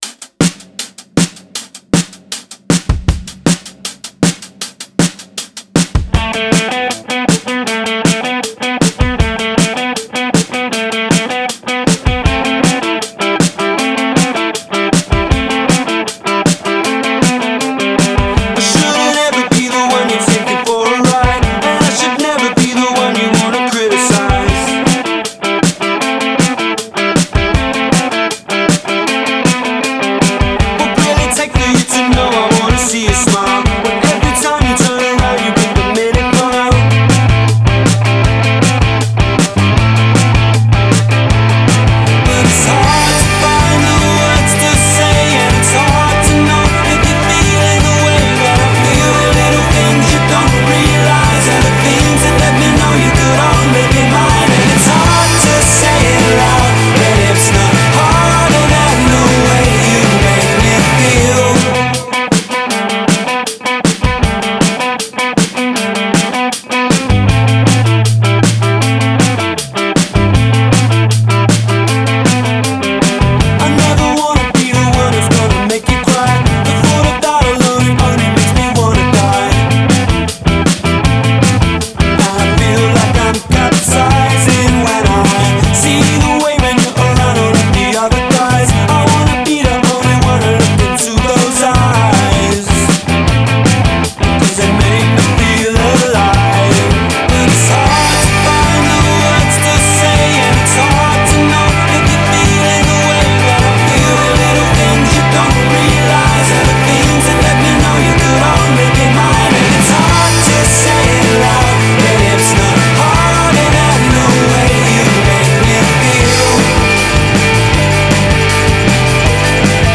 Poprock is primarily a guitar-based genre.